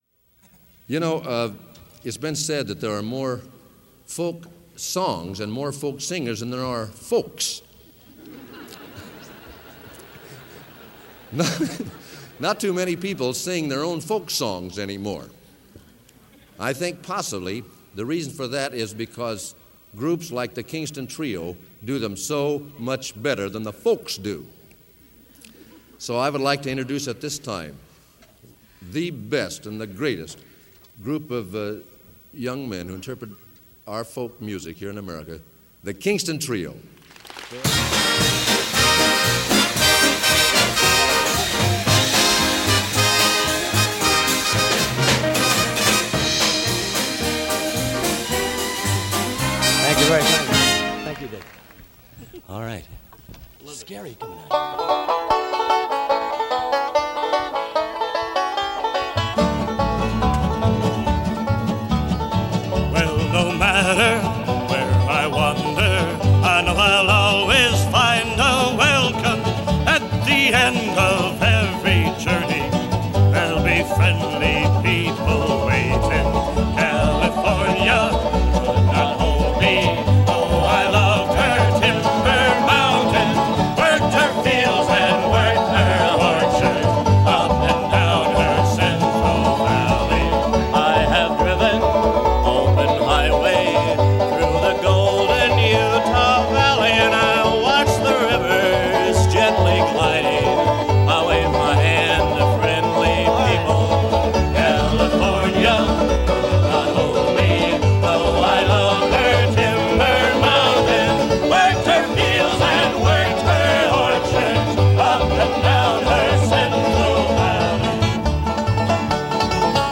Folk Music